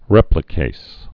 (rĕplĭ-kās, -kāz)